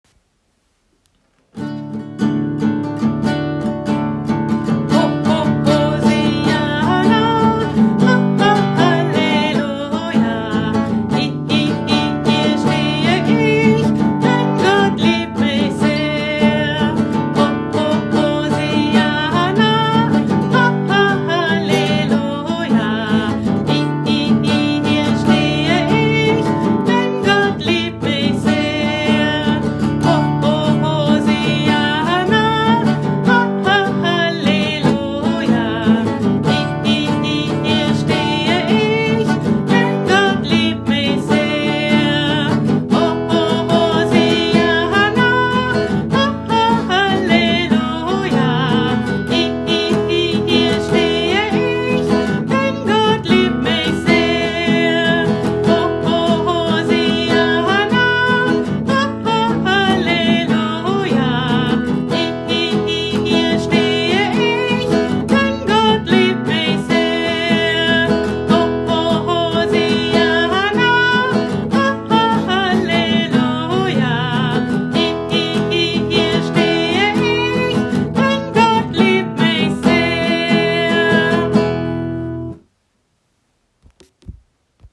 Kinderkirche für den 19.03.2021
Aber bevor ihr die Geschichte zu hören und zu sehen bekommt, lade ich euch wieder ein, noch ein paar Bewegungslieder zu singen und euch dazu zu bewegen:
Und zum Schluss noch ein neues Lied: